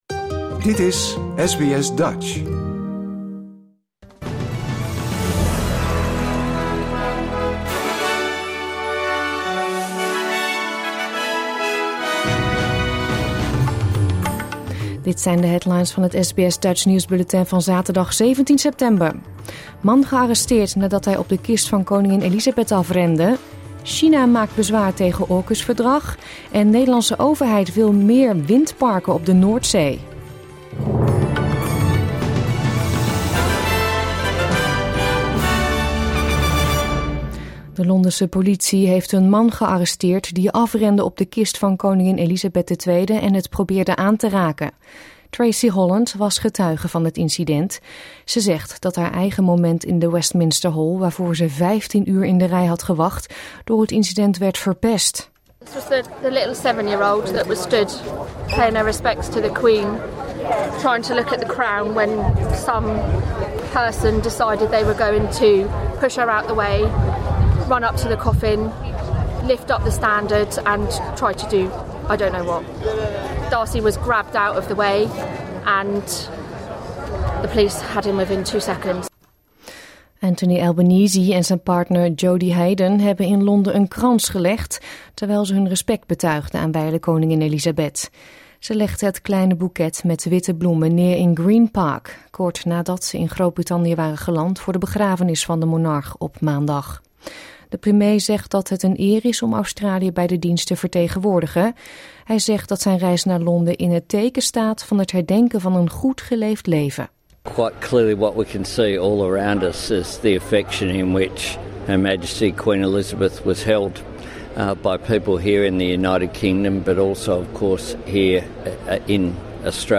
Nederlands / Australisch SBS Dutch nieuwsbulletin van zaterdag 17 september 2022